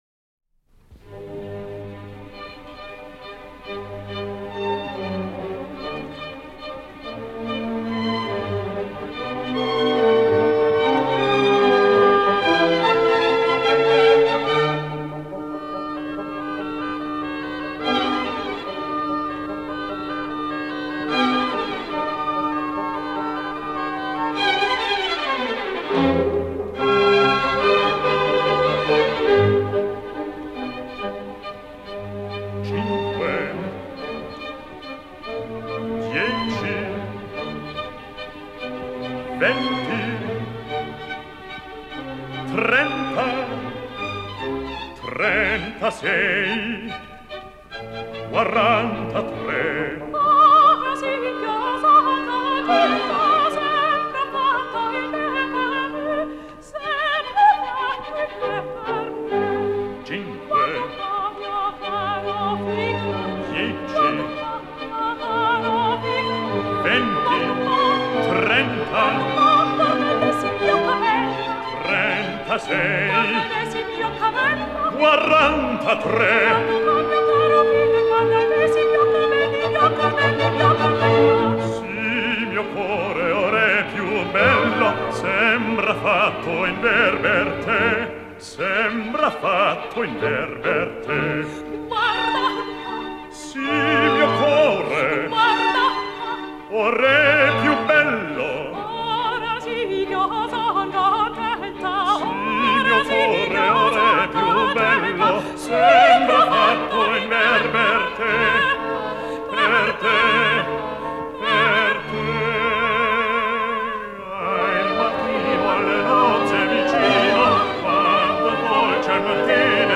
Mit seiner durch und durch individuellen Komik, seiner angenehmen Bassbaritonstimme und dem besonderen Charme seiner Persönlichkeit eroberte er sich rasch eine bevorzugte Position beim Wiener Opernpublikum.